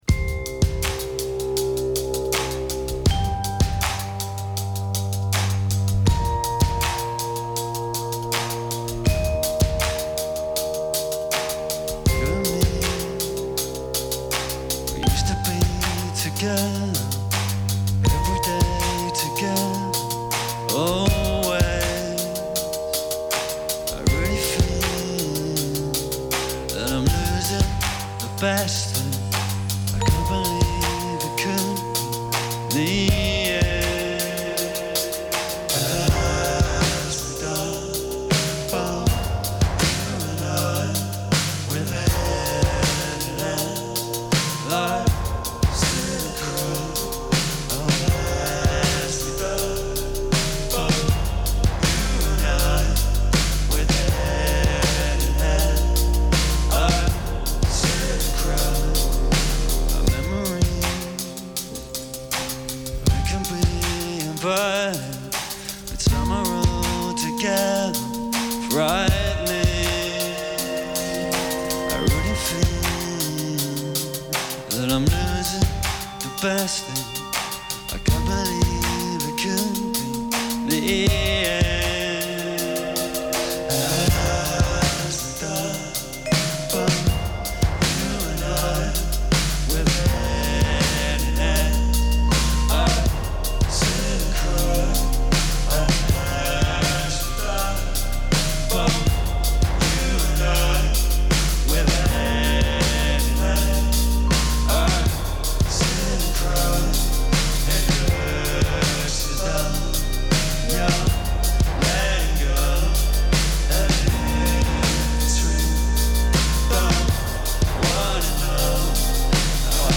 in session
English experimental rock outfit